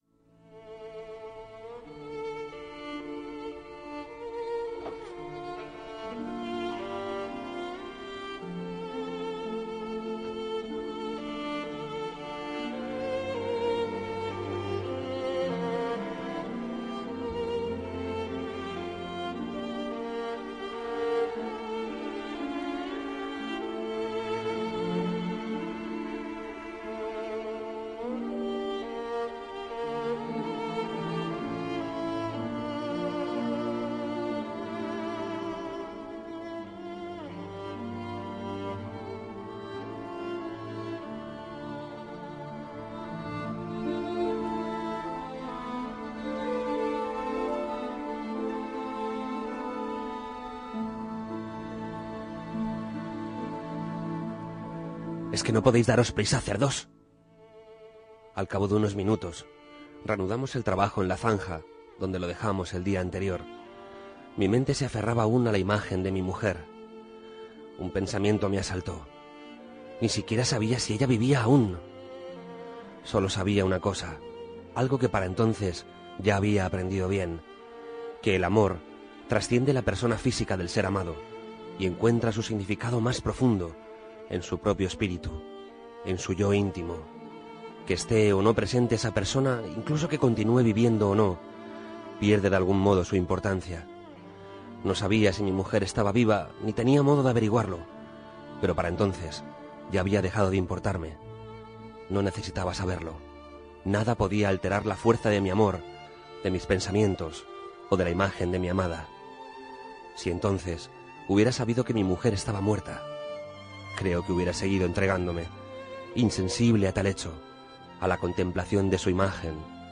Locución